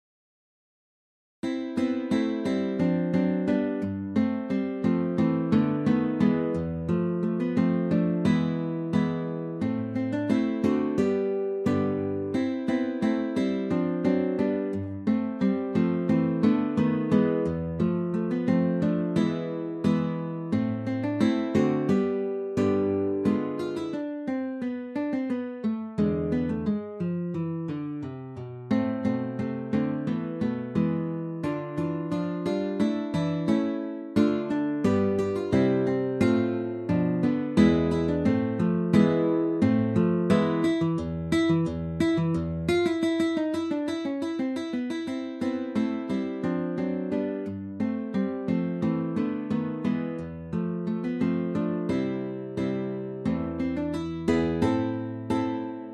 Insieme scrissero la raccolta di brani per chitarra ALBUMSBLADE, la cui parte preminente è di Frederik.
Ed, infine, eccovi un brano dalle sonorità “moderne”: